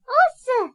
ボイス 00:00 / 00:00 ダウンロード 中性_「許せねーっ」 リアクション 中性 中音 怒 詳細はこちら ボイス 00:00 / 00:00 ダウンロード 中性_「俺のっ勝ちだ！！」